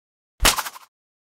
Звуки рвущейся веревки или каната, натягивание и связывания для монтажа видео в mp3
1. Звук рвущейся веревки